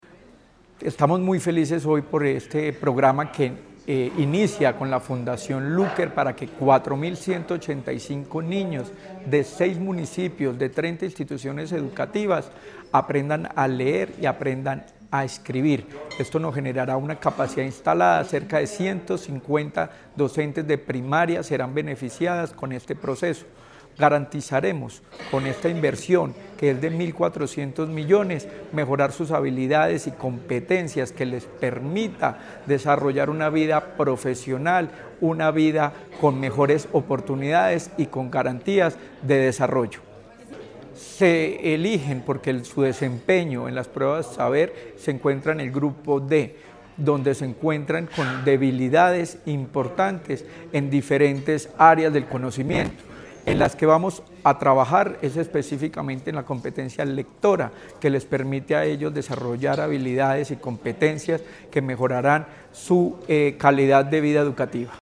Secretario-de-Educacion-Luis-Herney-Vargas-rueda-de-prensa.mp3